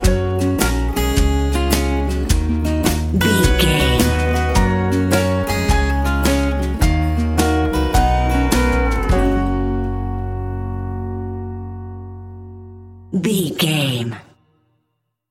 Ionian/Major
drums
acoustic guitar
violin